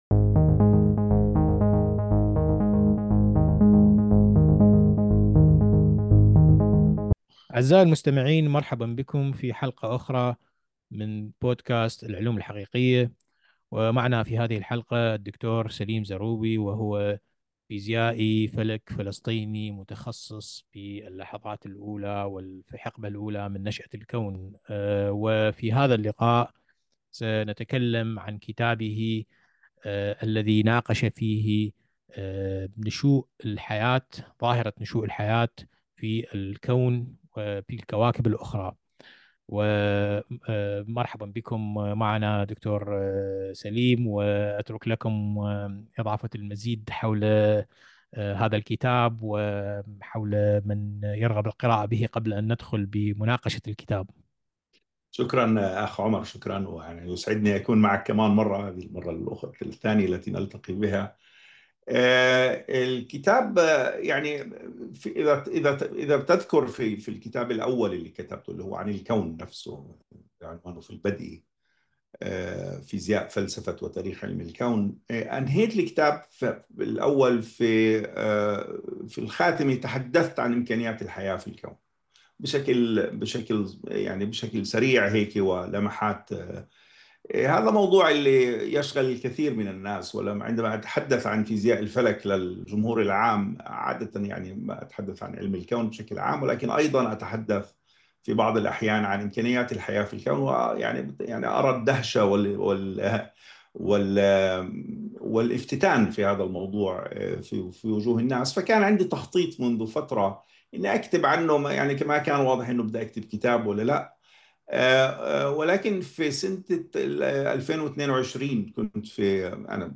في حلقة أخرى من بودكاست العلوم الحقيقية نحاور